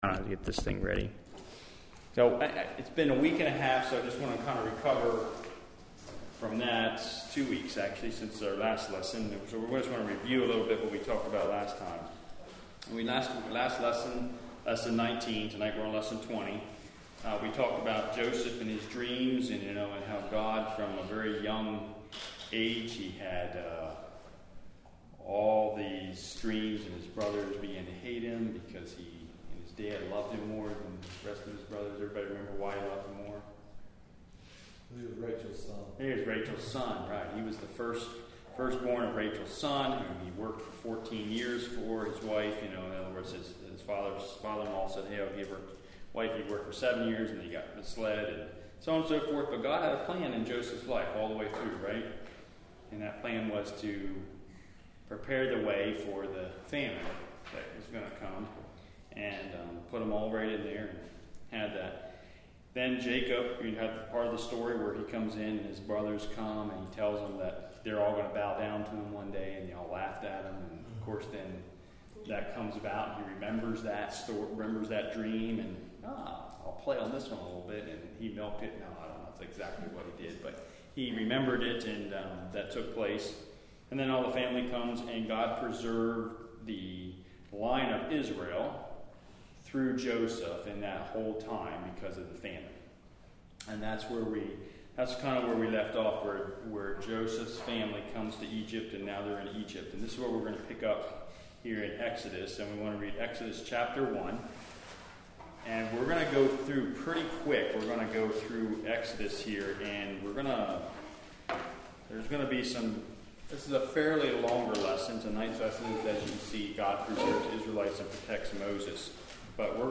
Lesson 20